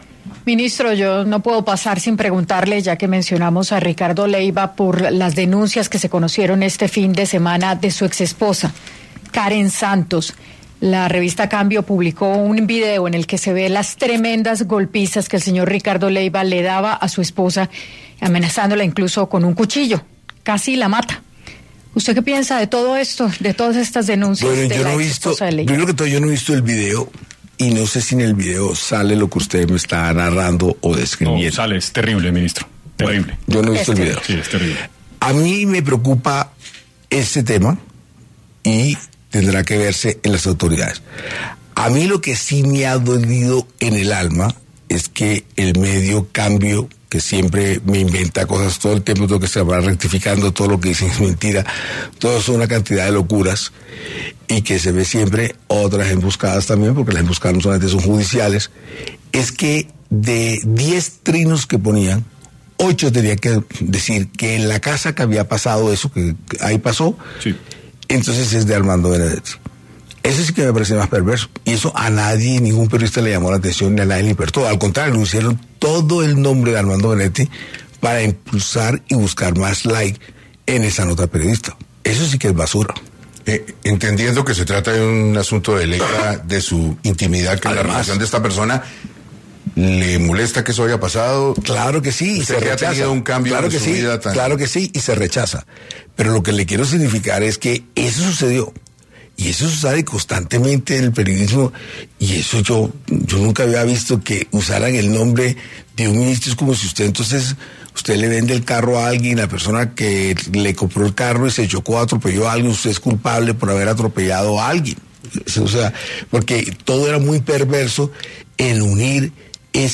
En la entrevista que el ministro Armando Benedetti le dio a 6AM de Caracol Radio, al preguntársele por este tema y qué piensa al respecto de estas denuncias, el ministro indicó que él no ha visto el video y si es cómo lo narran: “a mí me preocupa este tema y tendrá que verse en las autoridades”, aseguró.